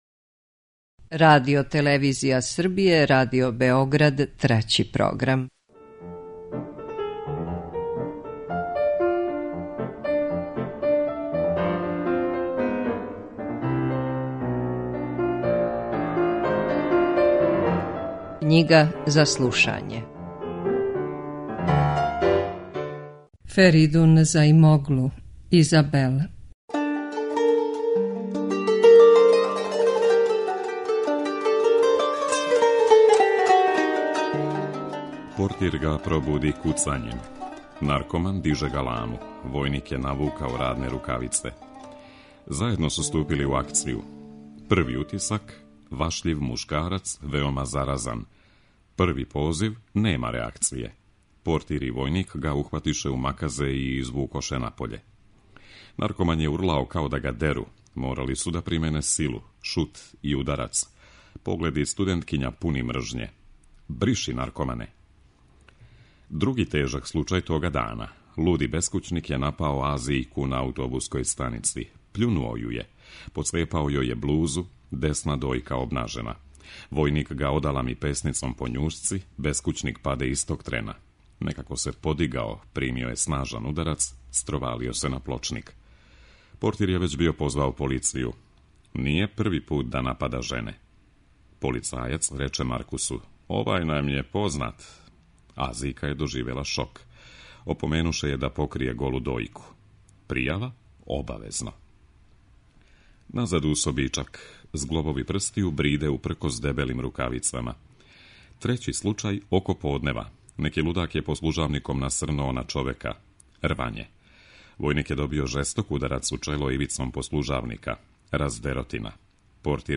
Књига за слушање